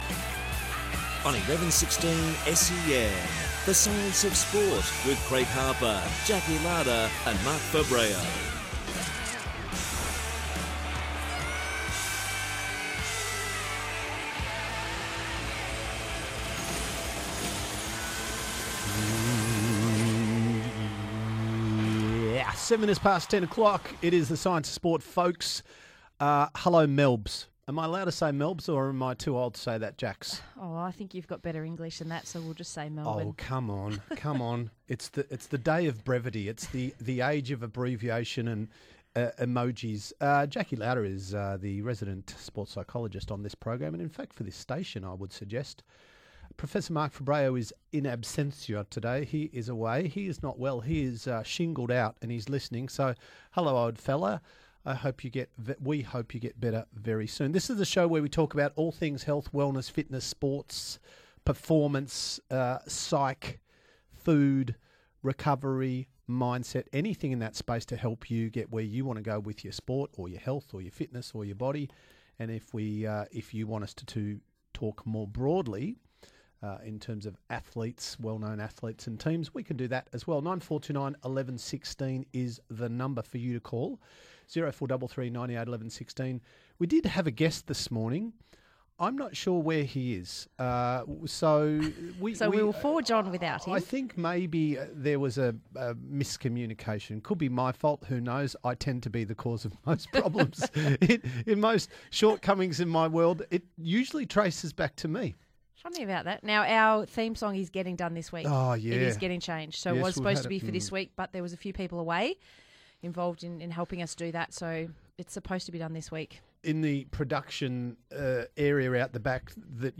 It might be a lazy Sunday morning but the pace was fast and furious in the SEN1116 Studio for The Science of Sport!